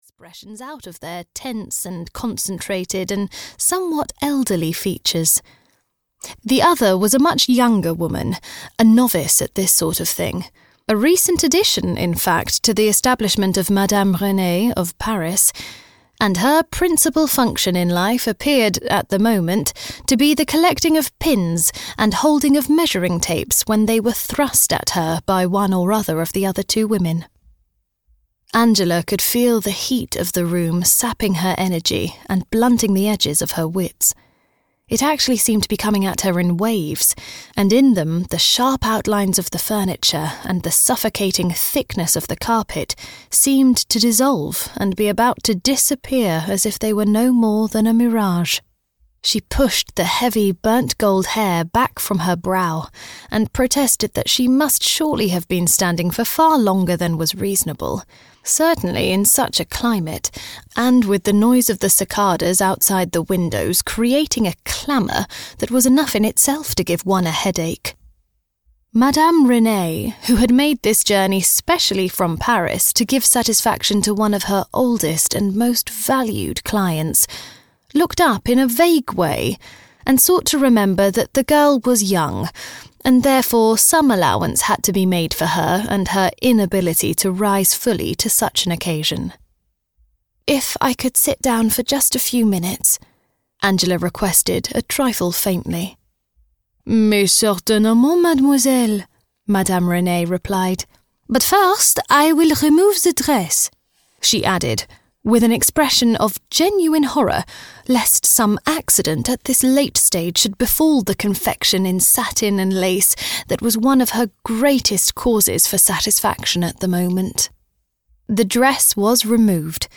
Night of the Singing Birds (EN) audiokniha
Ukázka z knihy